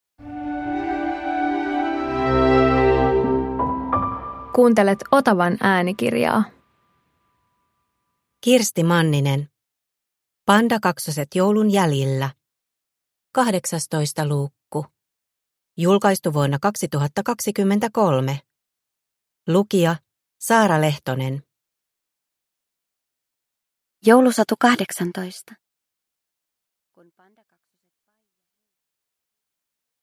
Pandakaksoset joulun jäljillä 18 – Ljudbok